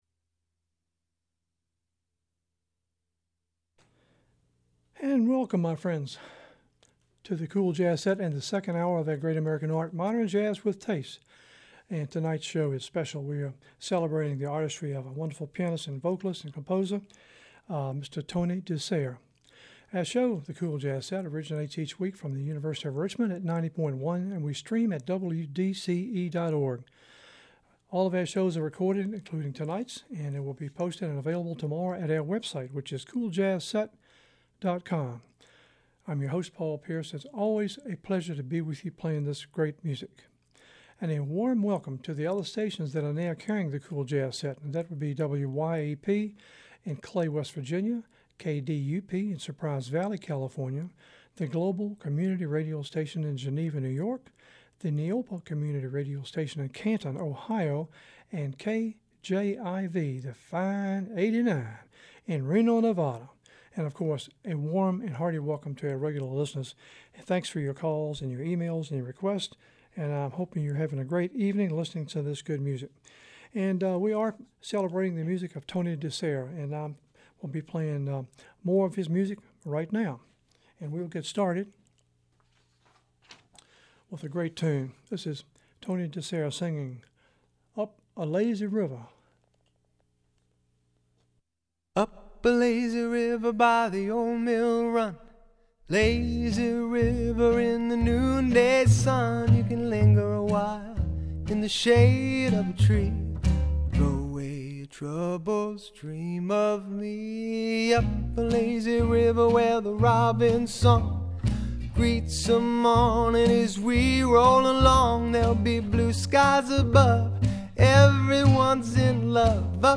cool jazz set